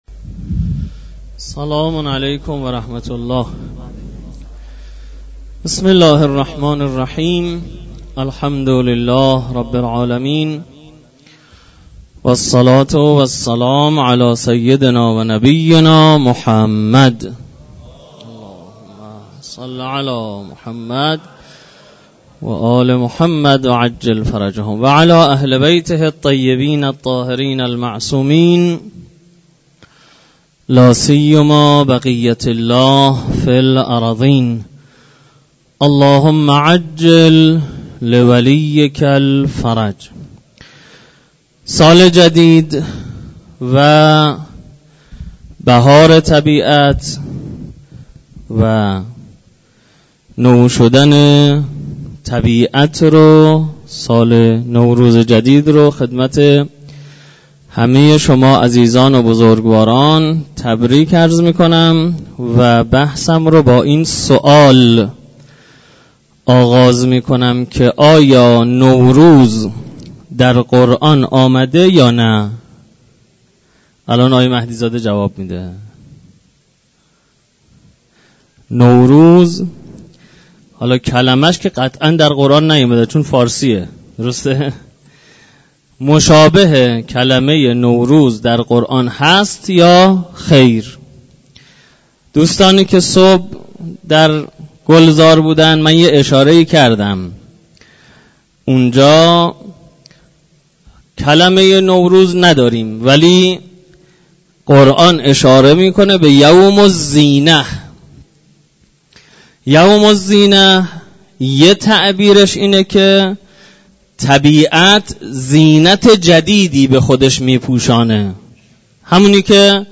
در مسجد دانشگاه کاشان